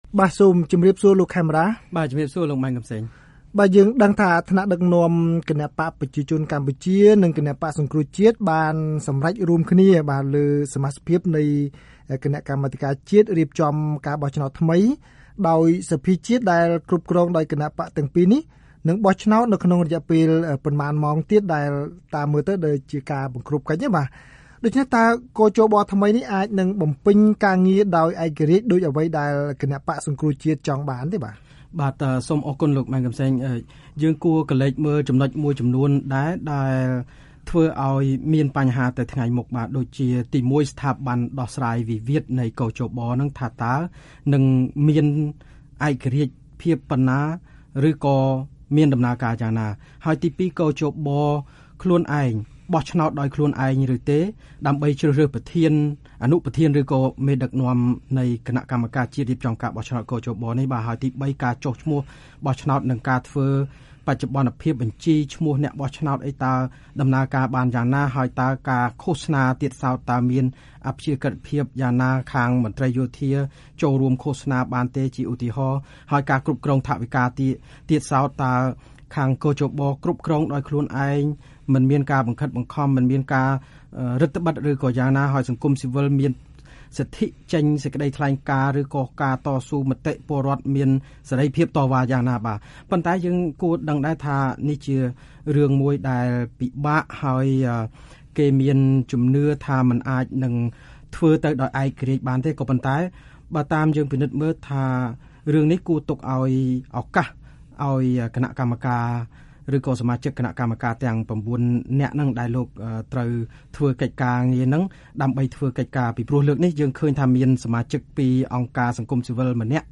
វិភាគ៖ សមាសភាពគ.ជ.ប.ថ្មីនិងការកោះហៅលោក កឹម សុខា ទៅតុលាការ